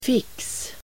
Uttal: [fik:s]